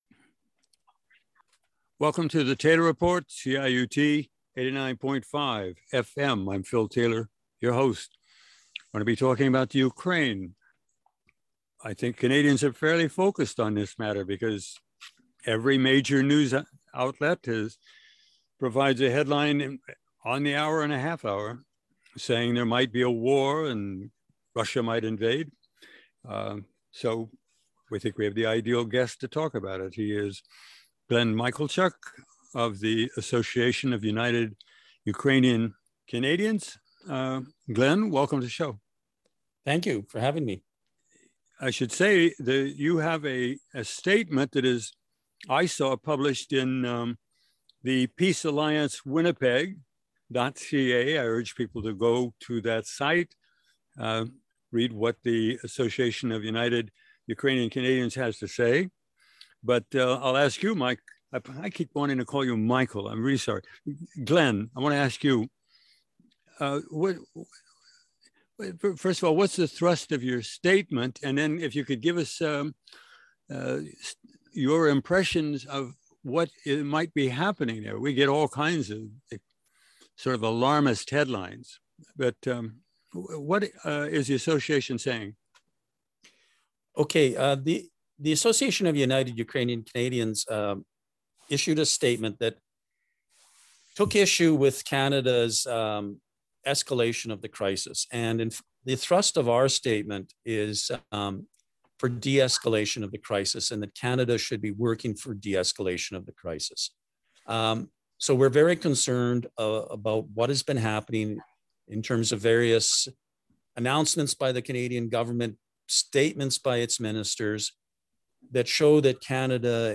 Program Type: Interview